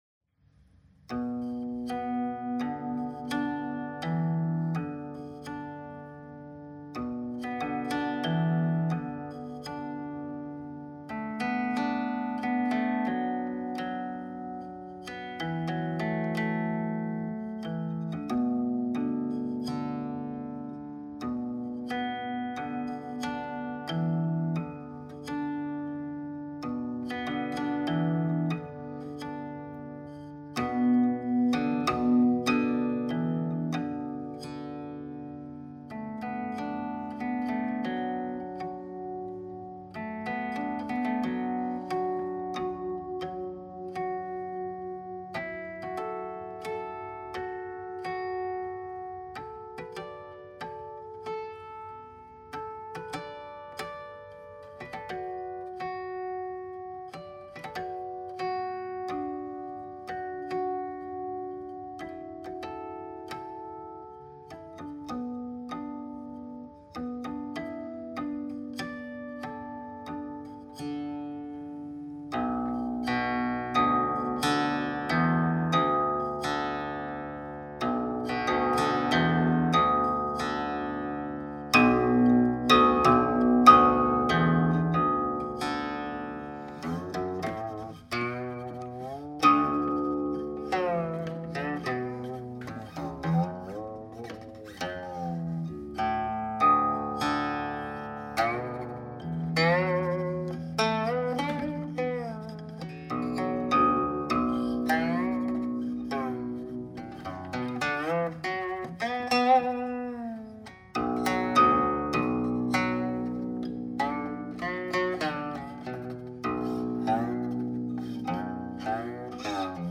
Гуцинь.
Основной принцип музыкального склада – , с вкраплением гармонических (октава, квинта, кварта, реже диссонансы – септима или секунда), которые призваны обогатить звучание и придать выразительность сюжетным узлам: см., например, пьесы «Скорбь разлучённого» («Лисао», 离骚) и «Осенние гуси» («Цюхун», 秋鸿) из собрания «Чудесные тайные ноты» («Шэньци мипу», 1425).